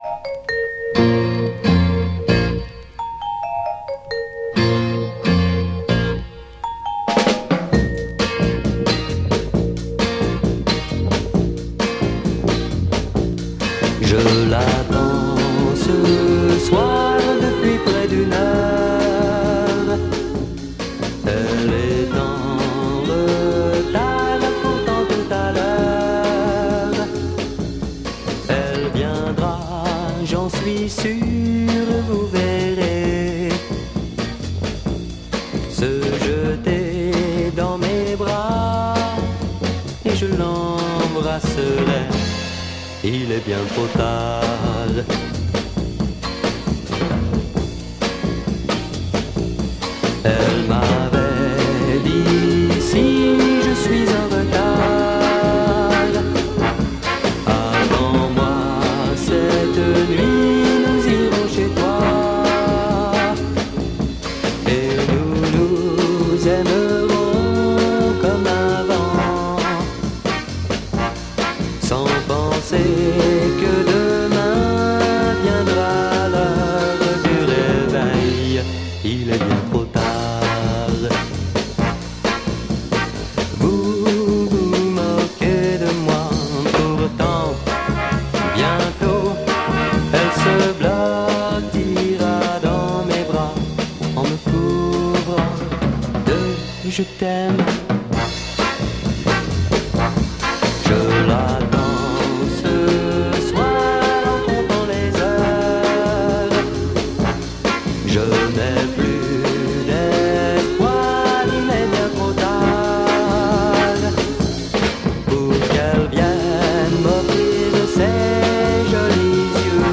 French Minet Mod Jazz vocal mid 60s EP
jazzy mod with a superbe groove!